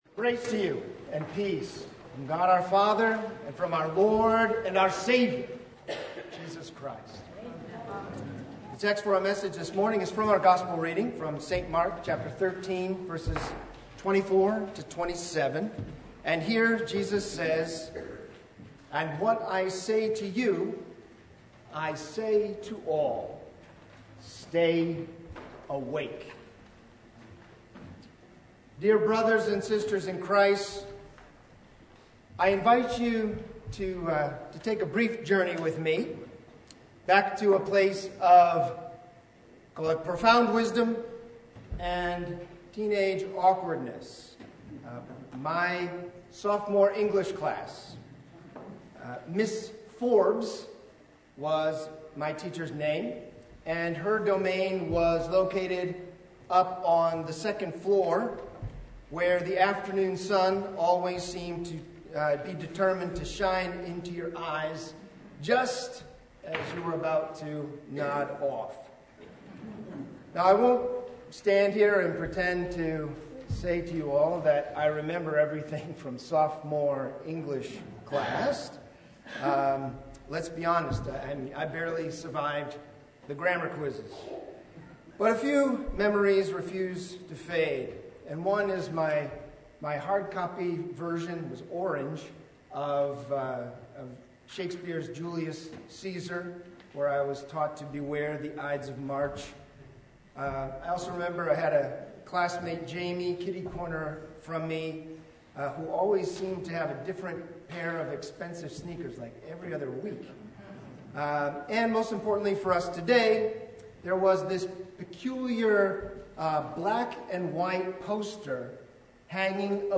Sermon from Christ the King (2024)
Preacher: Visiting Pastor